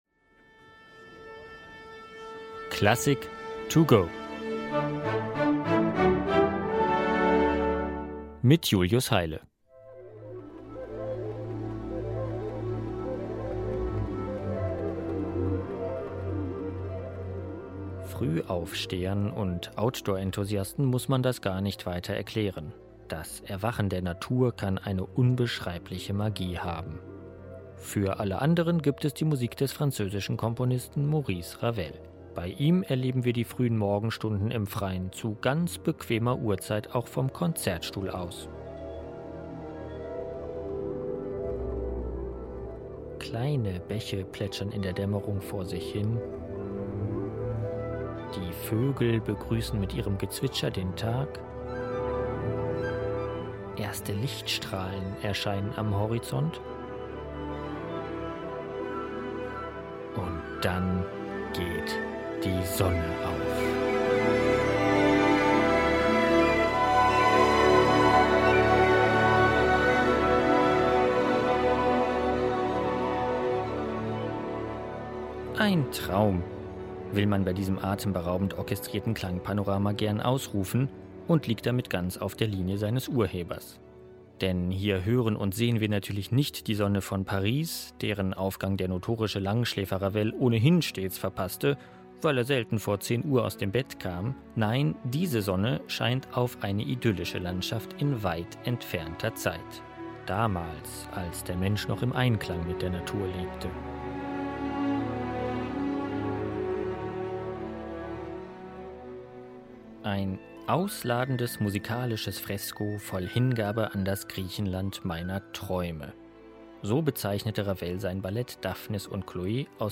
Werkeinführung für unterwegs.